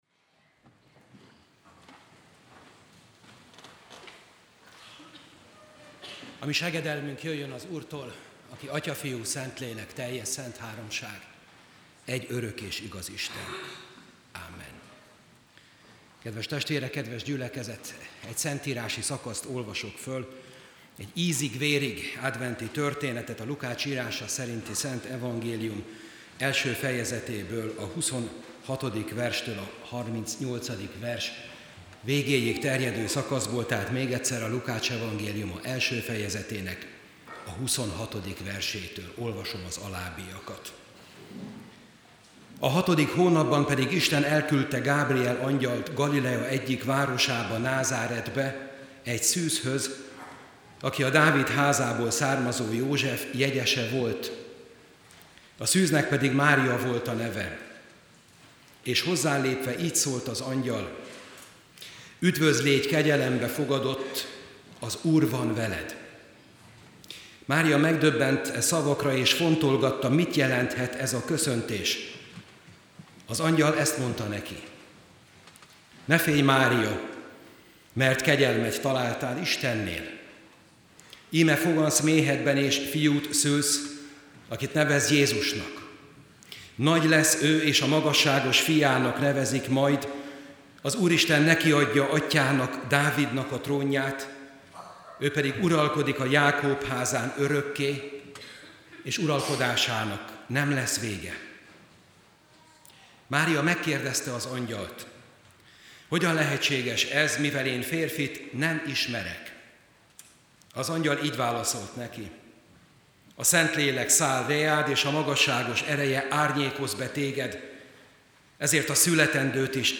07predikacio.mp3